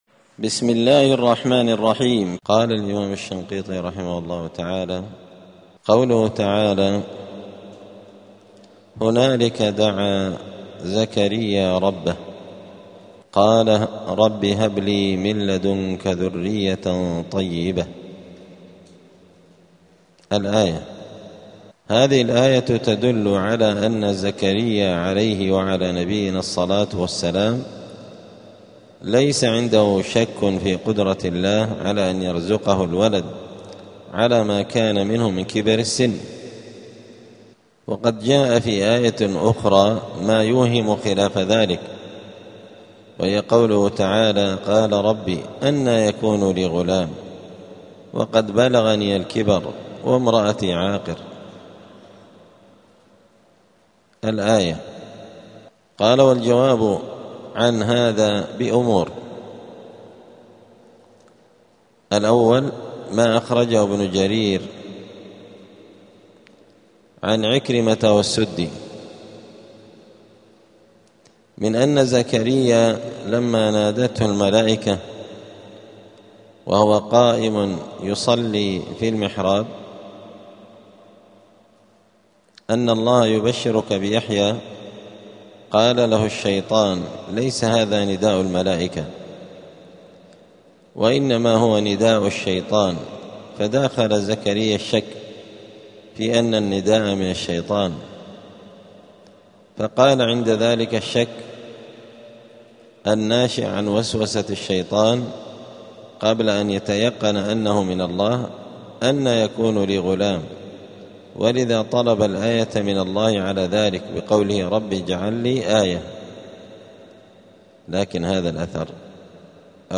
*الدرس الخامس عشر (15) {سورة آل عمران}.*